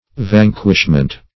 Search Result for " vanquishment" : The Collaborative International Dictionary of English v.0.48: Vanquishment \Van"quish*ment\, n. The act of vanquishing, or the state of being vanquished.